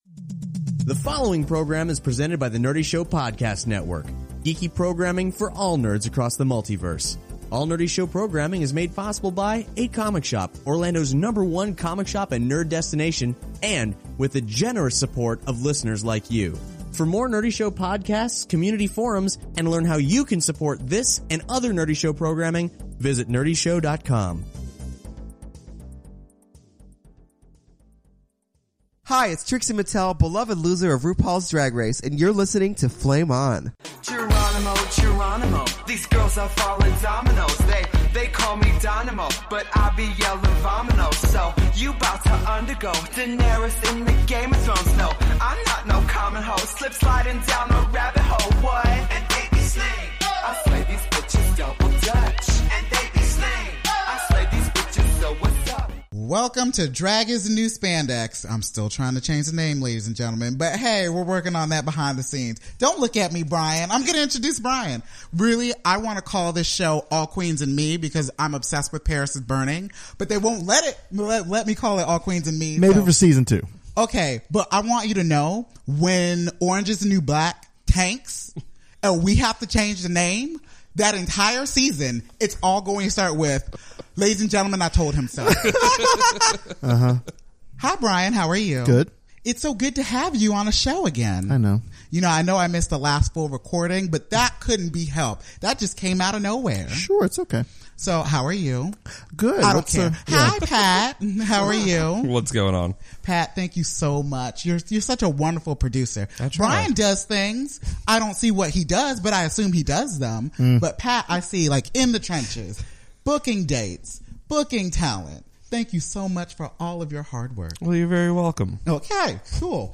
She took the internet by storm after she was wrongfully sent home from Ru Paul's Drag Race, and now Trixie Mattel is sitting down with the boys of Flame ON! to set the record straight...well, as much as she is contractually allowed to anyway. Find out Trixie's favorite games to play when she's out of drag, her true feelings on the other Season 7 queens, and who she thinks is coming back to rejoin the cast.